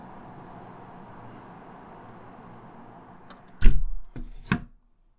ホテルの窓閉める
cls_wndw_safe_lock.mp3